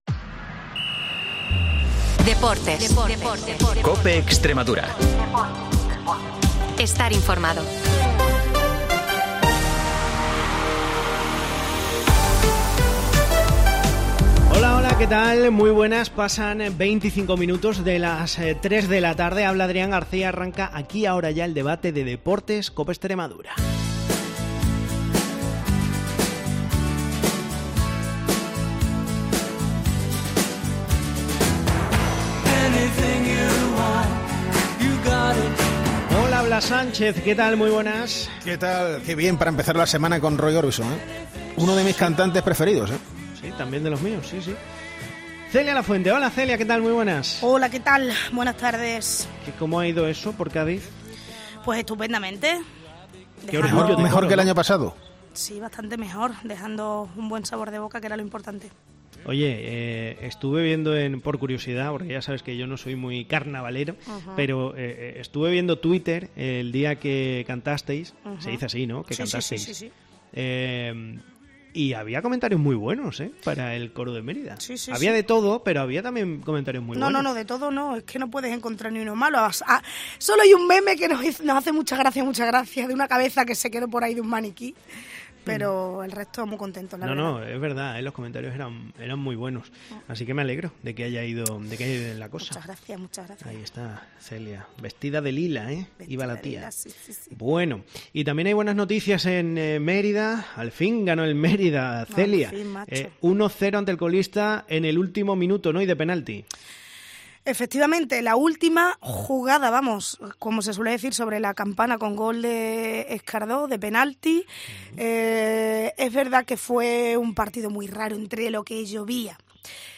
El debate de deportes de COPE Extremadura